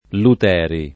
[ lut $ ri ]